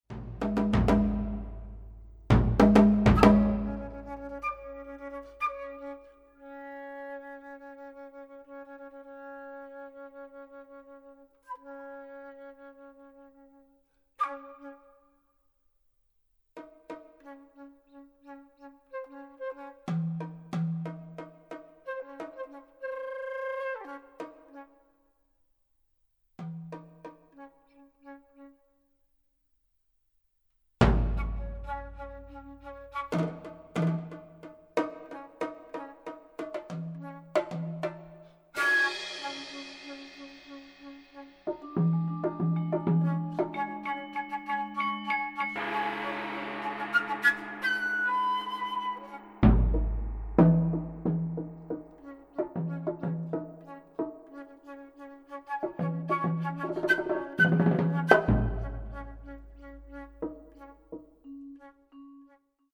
for alto flute/flute and Percussion.